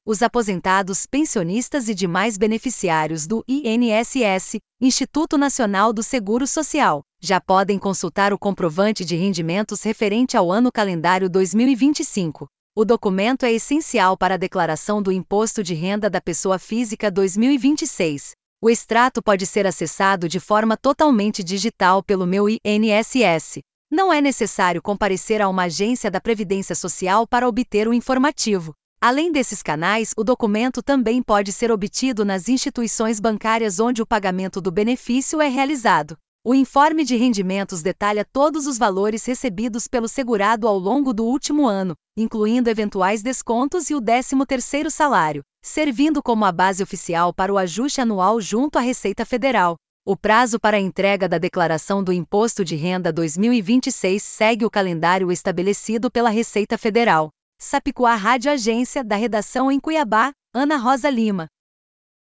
Boletins de MT 26 fev, 2026